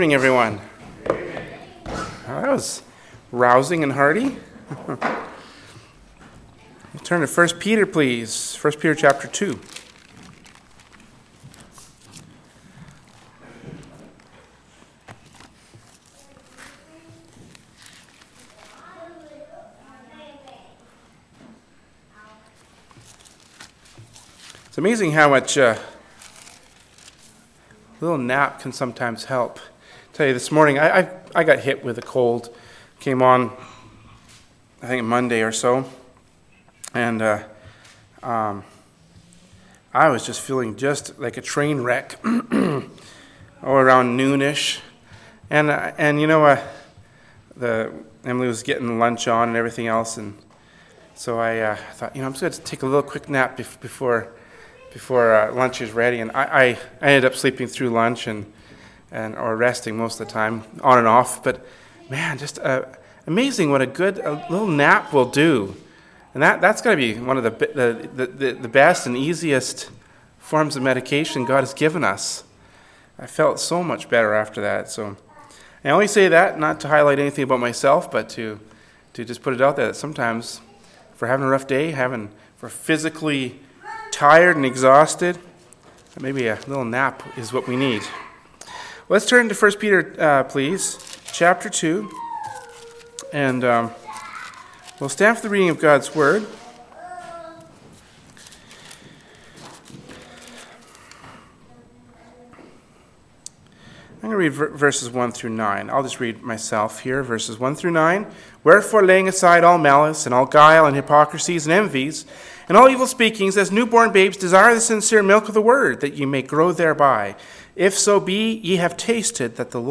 “1st Peter 2:1-9” from Wednesday Evening Service by Berean Baptist Church.